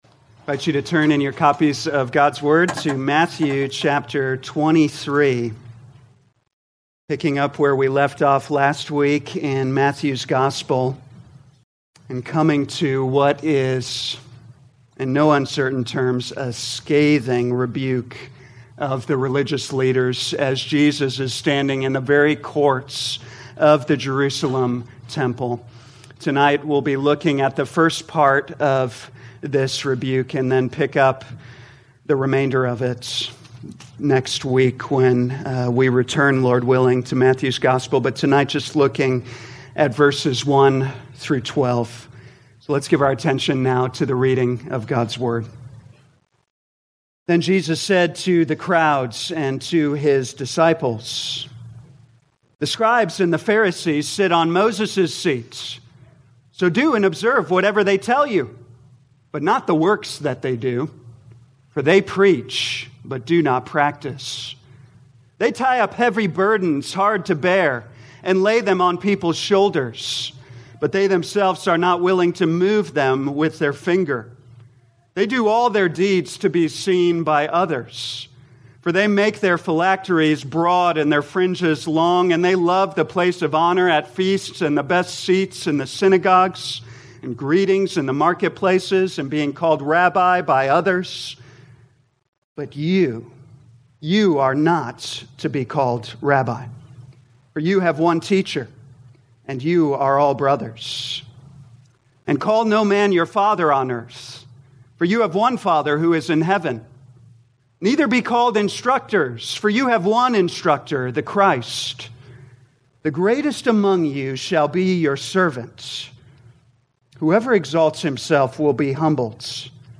2024 Matthew Evening Service Download: Audio Notes Bulletin All sermons are copyright by this church or the speaker indicated.